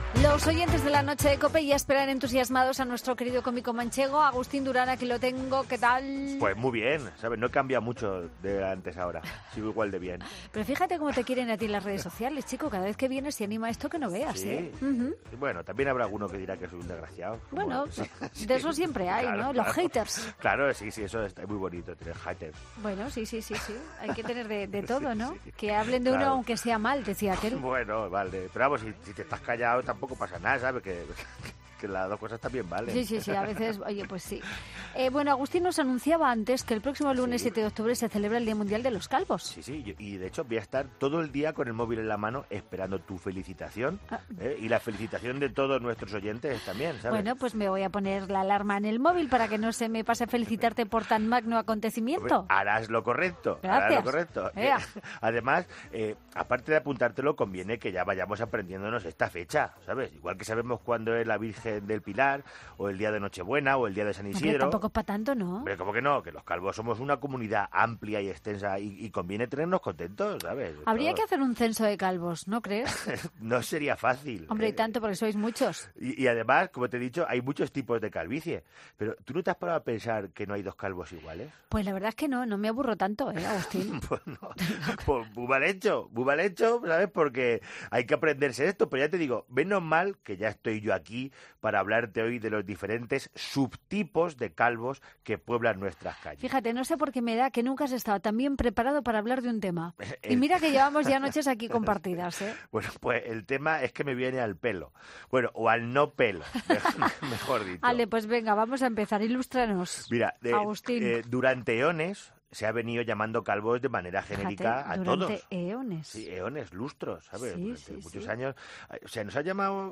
Redacción digital Madrid - Publicado el 05 oct 2019, 01:29 - Actualizado 16 mar 2023, 10:24 1 min lectura Descargar Facebook Twitter Whatsapp Telegram Enviar por email Copiar enlace Nuestro cómico manchego no se avergüenza de que le brille la azotea y nos hace un repaso de los diferentes tipos de calvicie. Siempre con música y mucho, mucho humor.